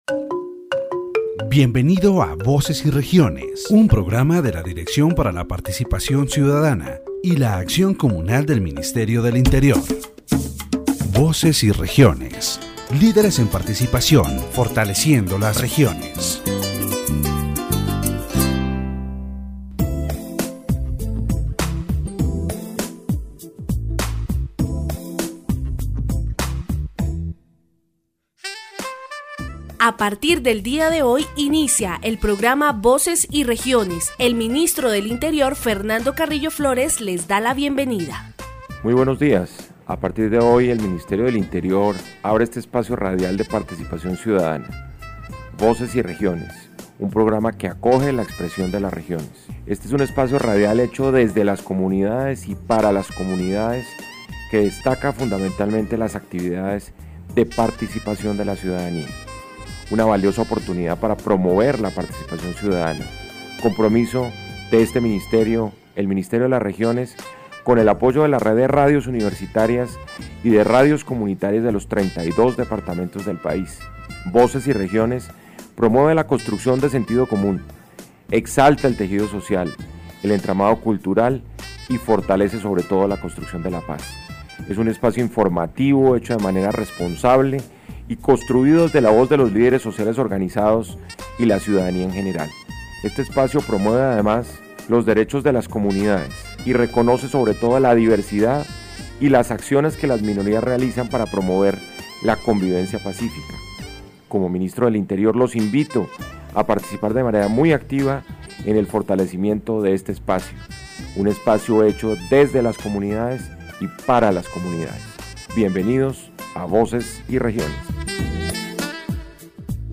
The objective of the program is to promote citizen participation and strengthen the regions through a radio space that highlights the activities and testimonies of the communities.